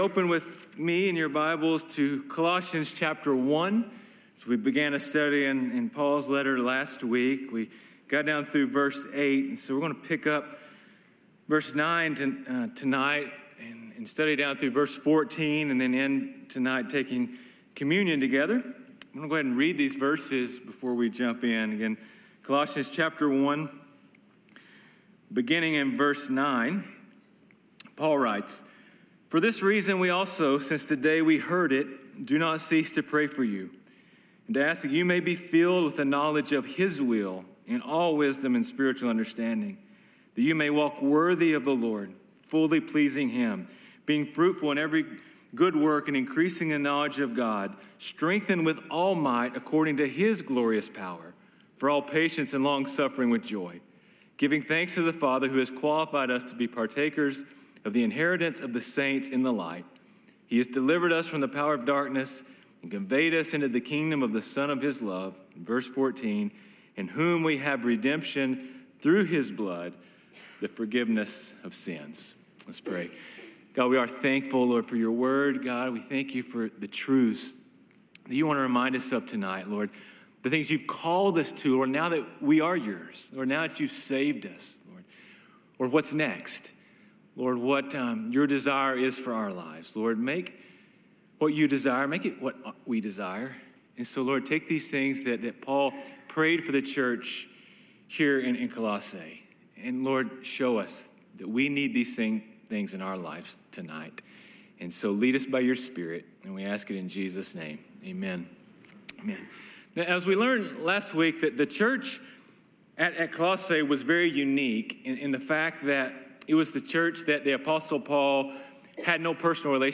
sermons Colossians 1:9-14 | What Christians Truly Need